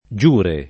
giure [ J2 re ] s. m.